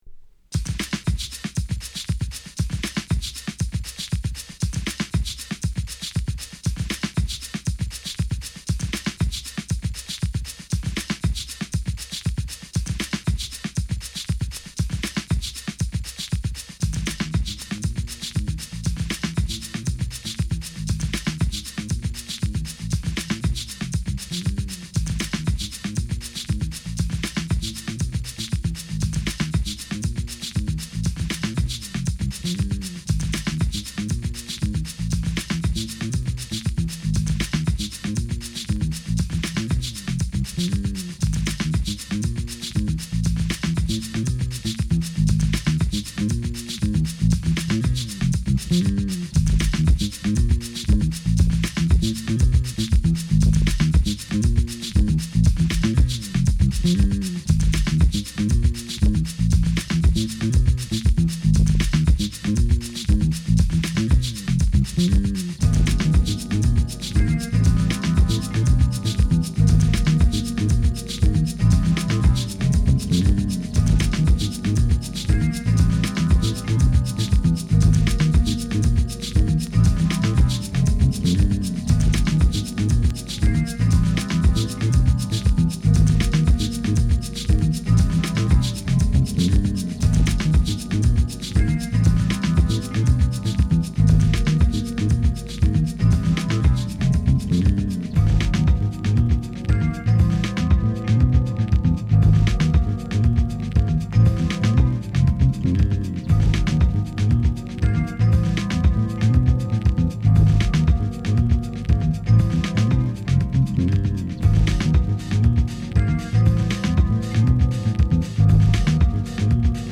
TOP > Detroit House / Techno > VARIOUS